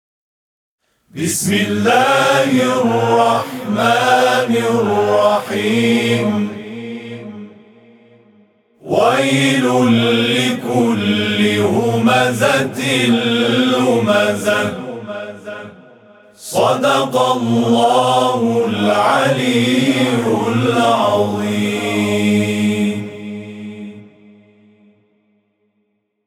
صوت همخوانی آیه یک سوره همزه از سوی گروه تواشیح «محمد رسول‌الله(ص)»